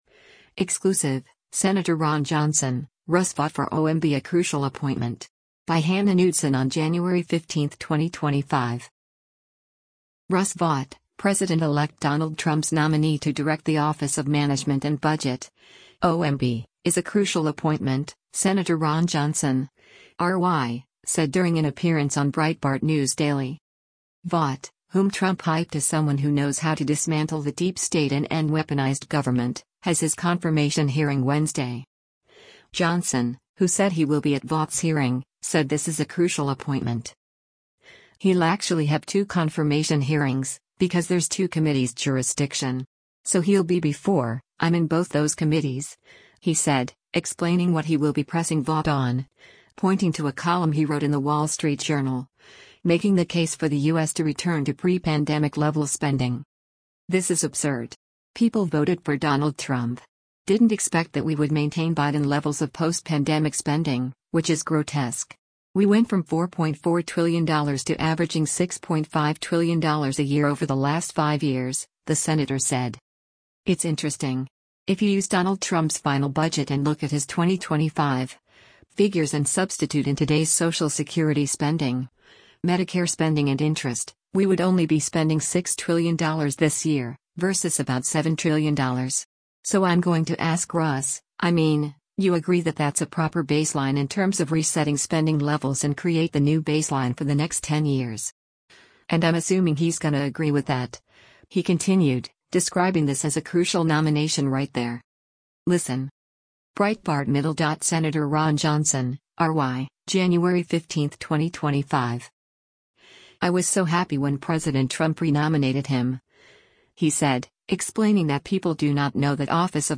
Russ Vought, President-elect Donald Trump’s nominee to direct the Office of Management and Budget (OMB), is a “crucial” appointment, Sen. Ron Johnson (R-WI) said during an appearance on Breitbart News Daily.
Breitbart News Daily airs on SiriusXM Patriot 125 from 6:00 a.m. to 9:00 a.m. Eastern.